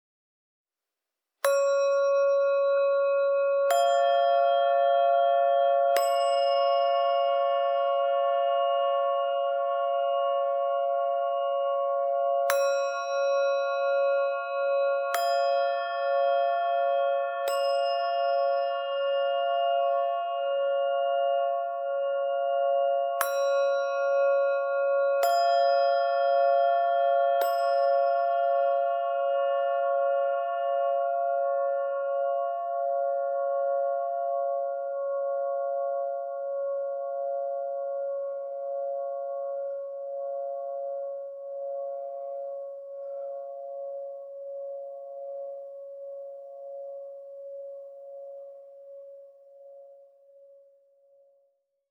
Meinl Sonic Energy Nagi Hanging Bell Set - 3 pcs (SBZ2)